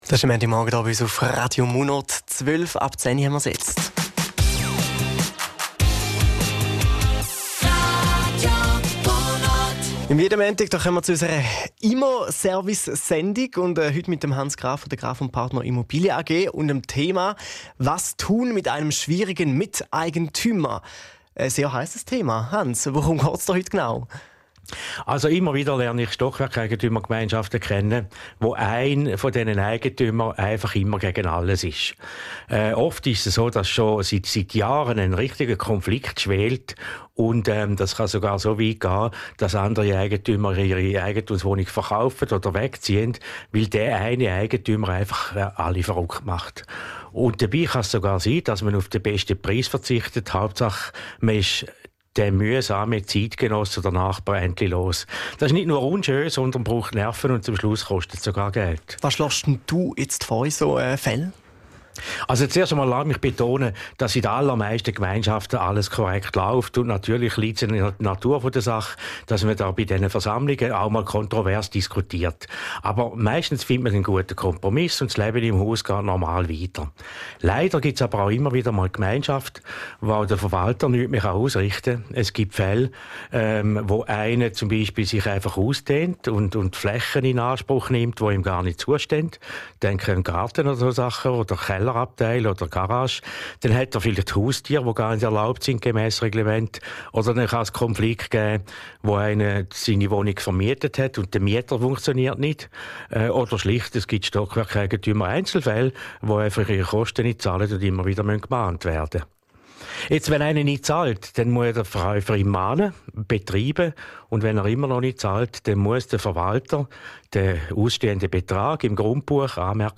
radio_munot_schwierige_miteigentuemer.mp3